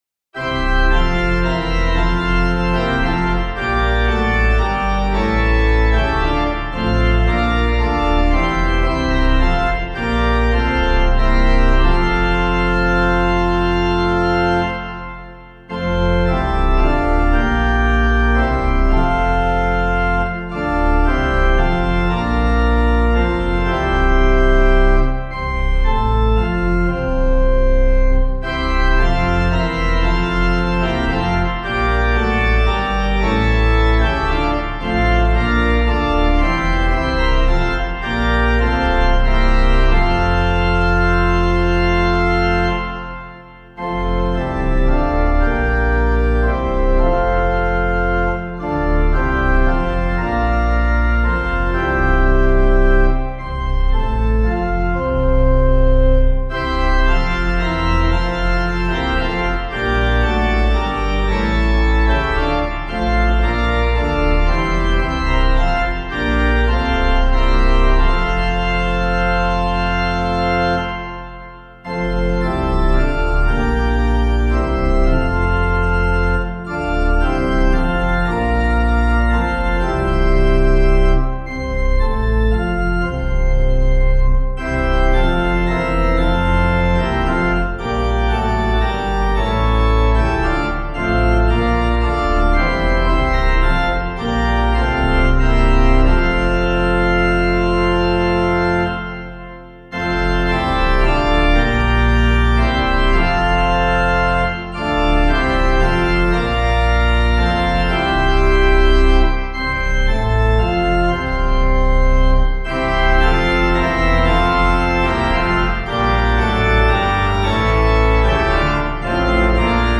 organpiano